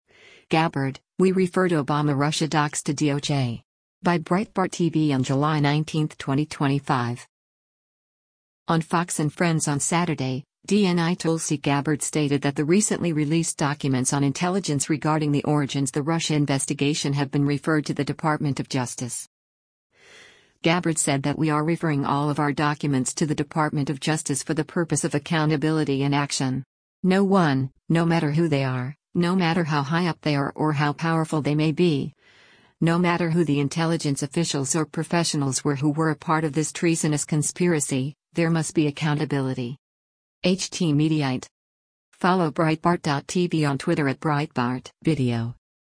On “Fox & Friends” on Saturday, DNI Tulsi Gabbard stated that the recently released documents on intelligence regarding the origins the Russia investigation have been referred to the Department of Justice.